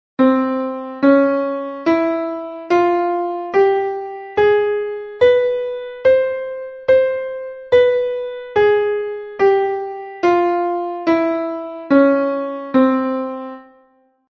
- The Arabic scale: it is used in the music of North Africa.
escaladoblearmonicarabe_.mp3